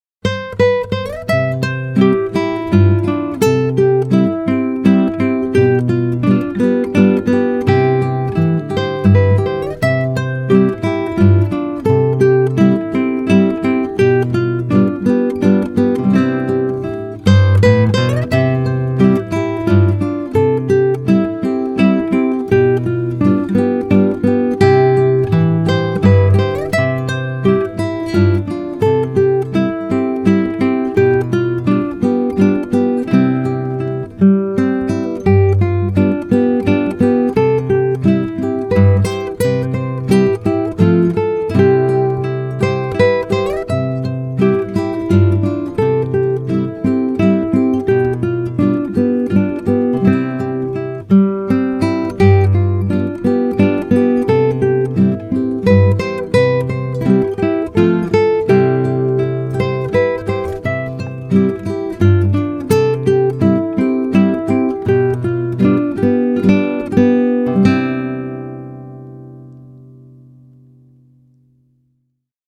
Mozart KV606 Nr.1 Gitarre 1 TAB
Ländler-Nr.-1-KV606.mp3